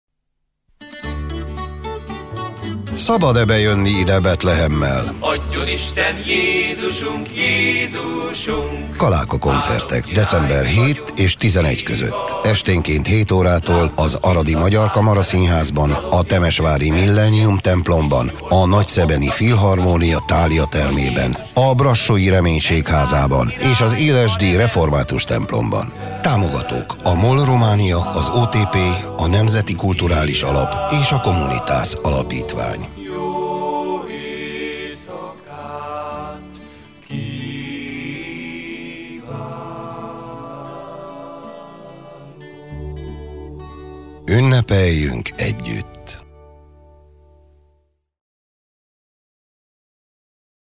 kalaka_-_betlehem_2015_radiospot.mp3